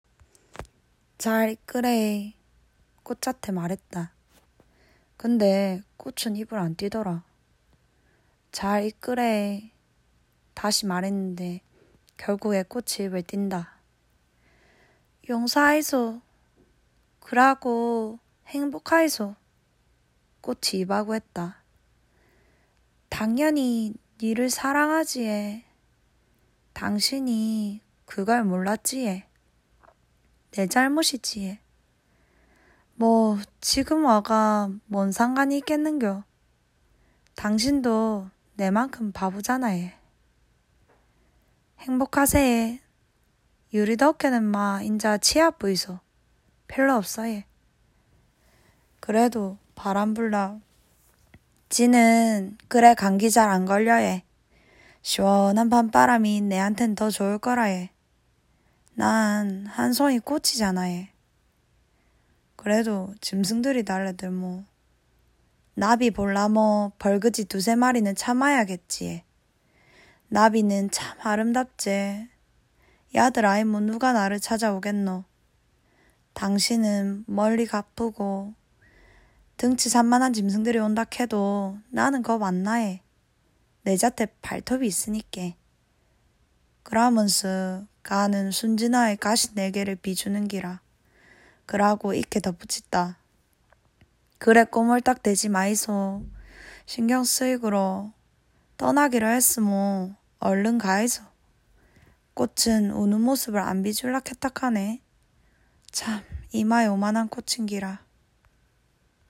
독서모임을 해서 가장 사투리를 구수하게 읽은 한 멤버가 직접 낭독해주는 파일을 들어보세요!
사투리로 이야기를 들으니, 장미꽃의 슬픈 마음이 여기까지 느껴지는 것 같지 않나요?